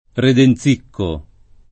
[ reden Z& kko ]